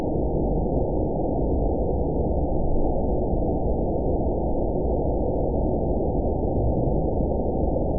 event 920363 date 03/18/24 time 23:20:37 GMT (1 month, 1 week ago) score 7.20 location TSS-AB07 detected by nrw target species NRW annotations +NRW Spectrogram: Frequency (kHz) vs. Time (s) audio not available .wav